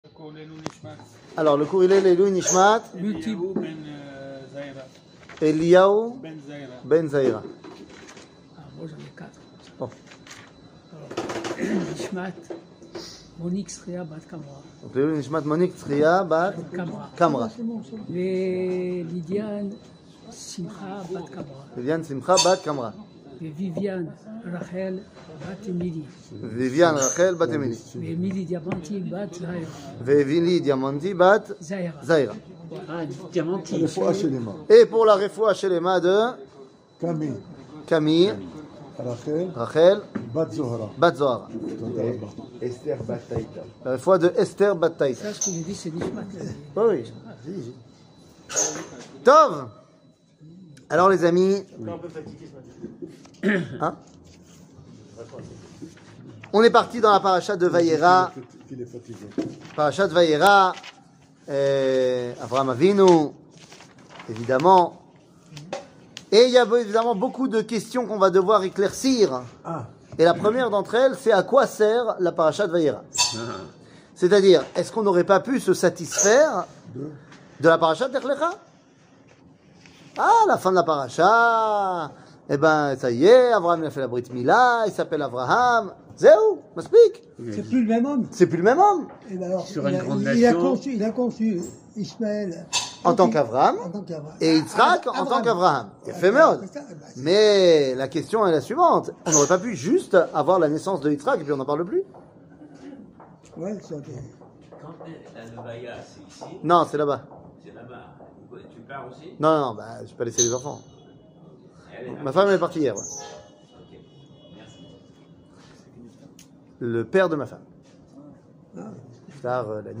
Parachat Vayera, Midat Adin, la Justice 00:28:29 Parachat Vayera, Midat Adin, la Justice שיעור מ 19 אוקטובר 2021 28MIN הורדה בקובץ אודיו MP3 (26.06 Mo) הורדה בקובץ וידאו MP4 (323.02 Mo) TAGS : שיעורים קצרים